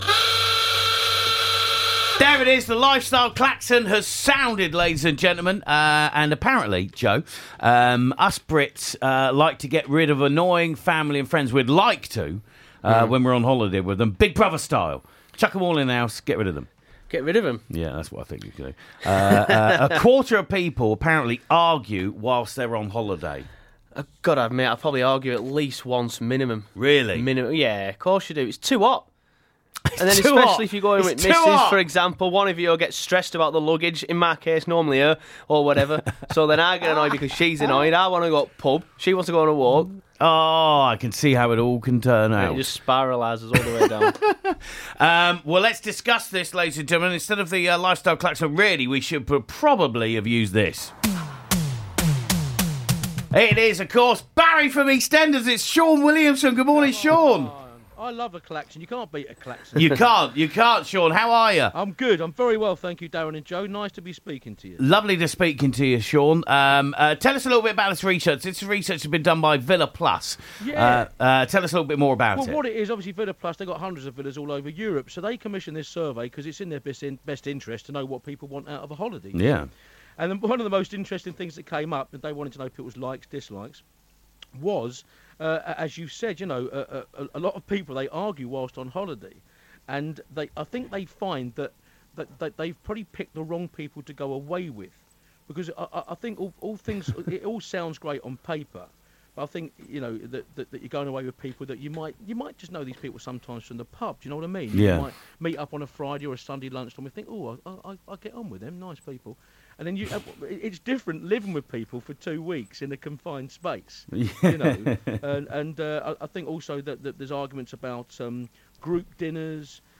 Barry From Eastenders on Radio Yorkshire!!!